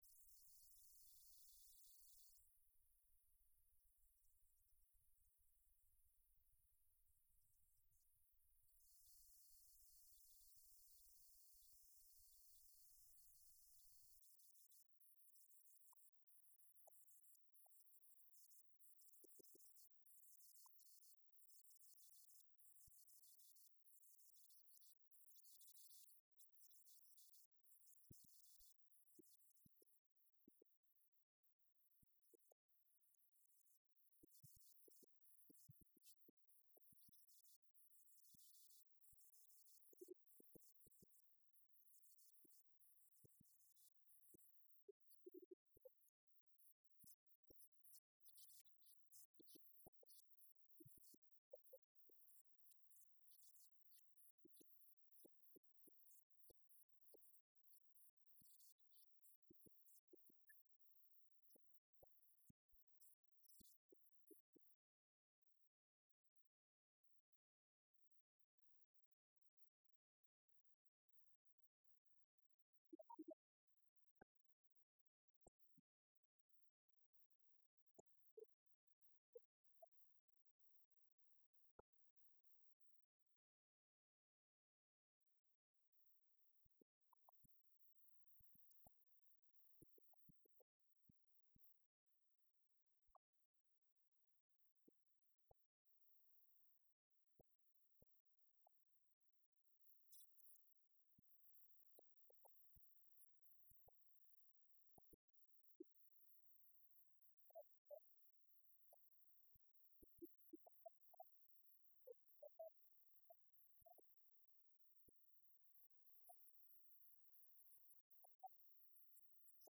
Phones 4u Arena
Lineage: Audio - AUD (Sony ECM-717 + Sony PCM-M10)